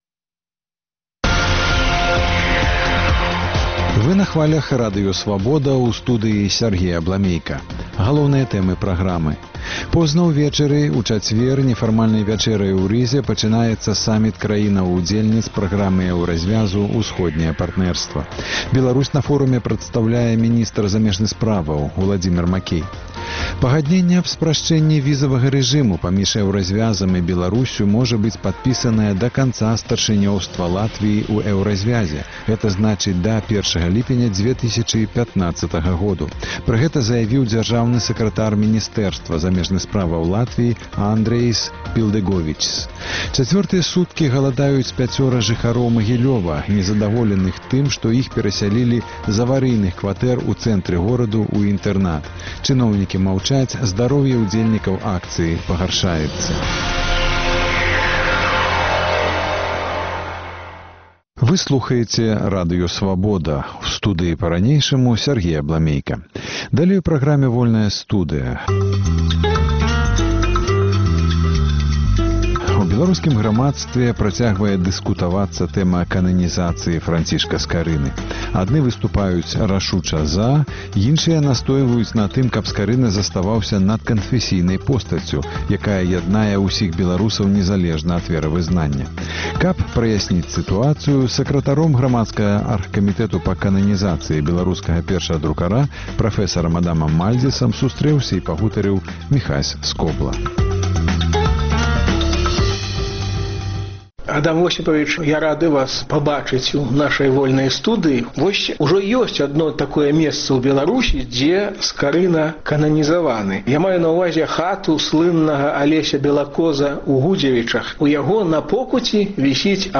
Госьцем перадачы — сакратар ініцыятыўнай групы па кананізацыі беларускага першадрукара прафэсар Адам Мальдзіс.